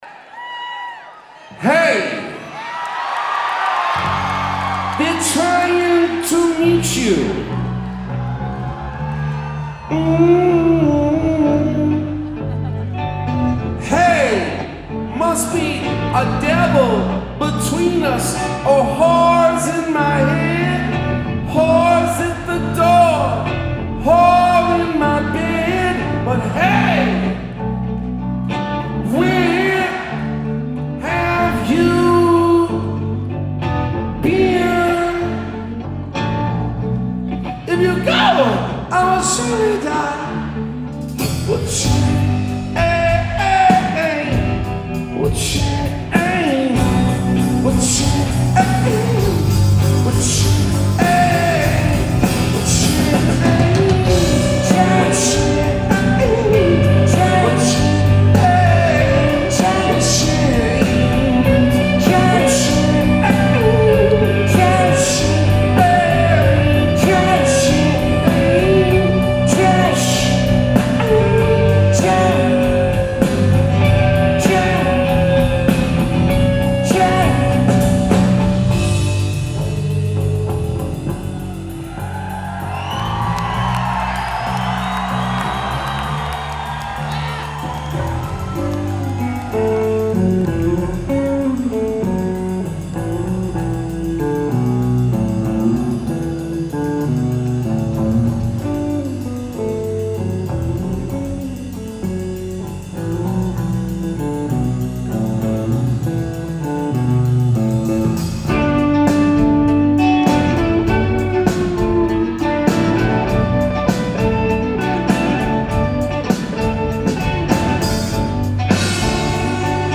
Live at the Wang Theatre / Citi Center
Audience recording
Mics = DPA 4061 > Custom BB > R09HR @ 24/96
Location = Front Row Mezzanine